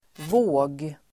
Uttal: [vå:g]